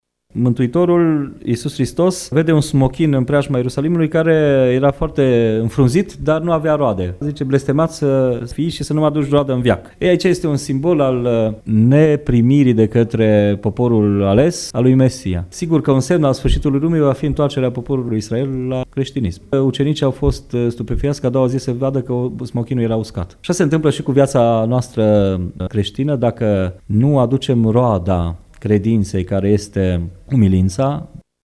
Preotul ortodox din Tg Mureş